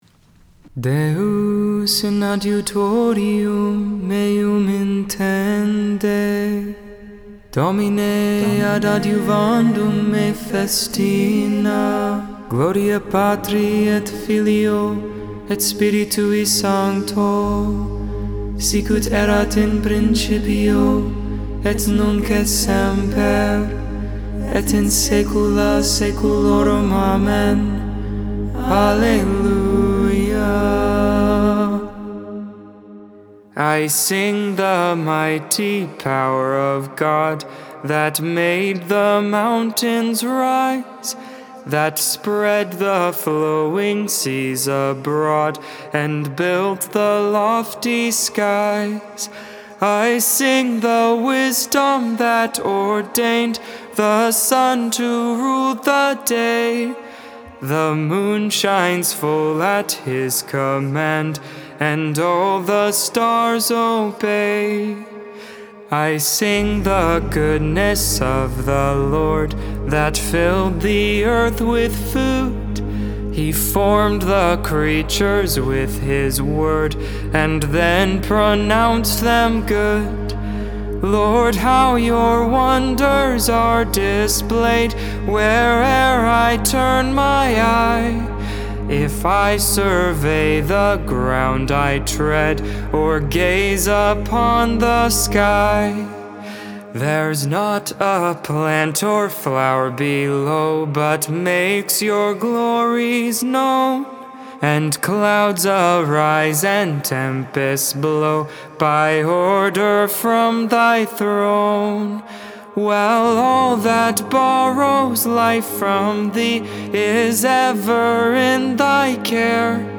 Lauds, Morning Prayer for the 19th Monday in Ordinary Time, June 13th, 2022.